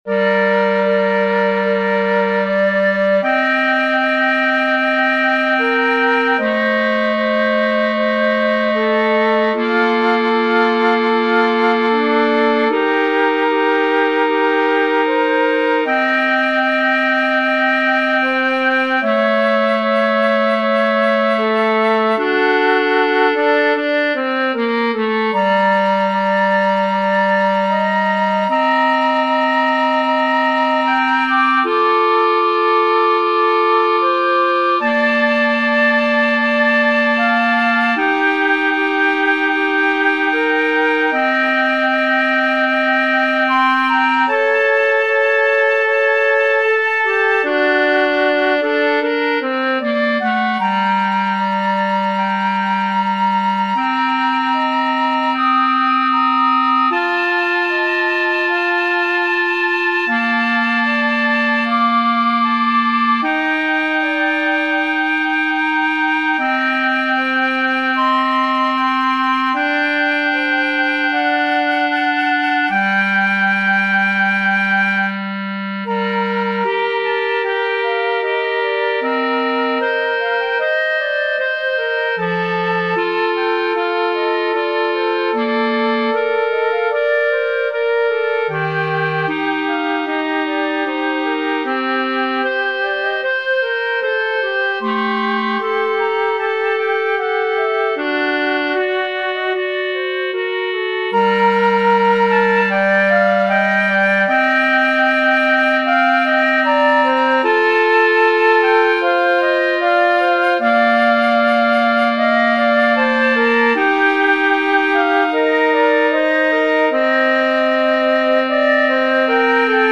編曲（PCで演奏）：
カッチーニのアヴェ・マリア（２Flutes＋Clarinet B♭）